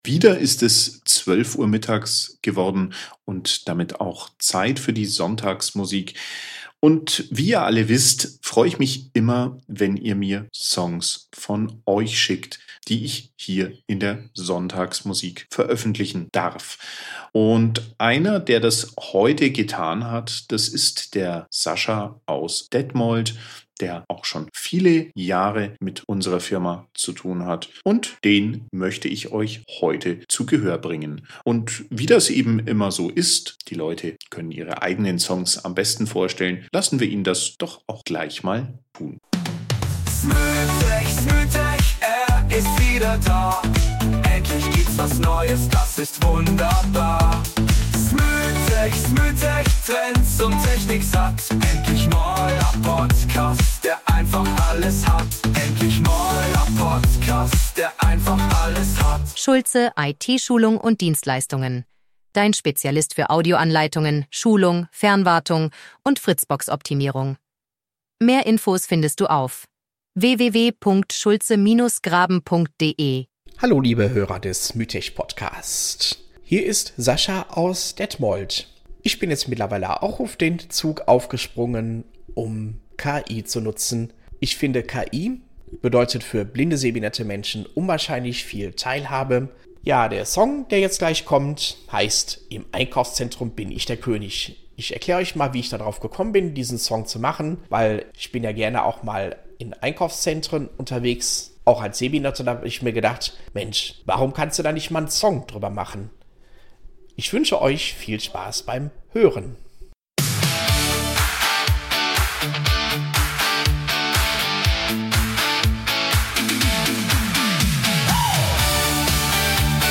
eingängiger Song, der zeigt, wie man mit Sehbehinderung das Leben